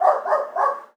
dog_bark_small_02.wav